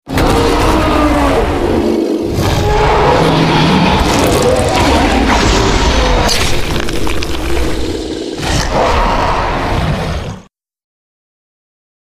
more gore sounds being used sound effects free download